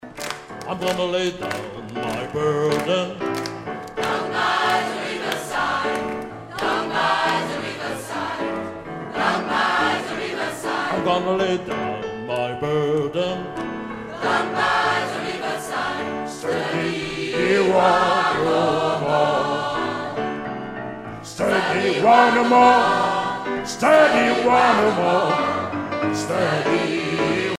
Edition discographique Live